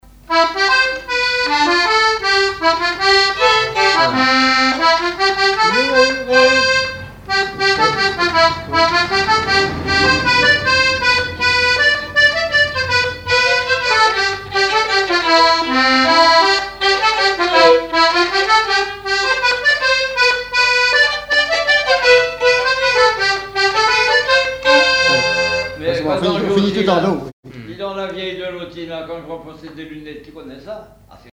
Résumé instrumental
danse : mazurka
Chansons, témoignages et instrumentaux